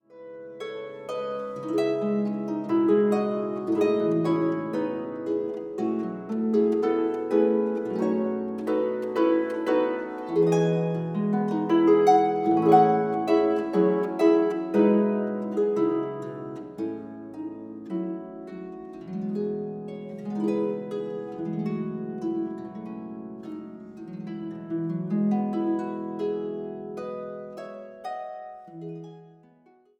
Ierse, Schotse, Engelse en Amerikaanse melodieën op de harp.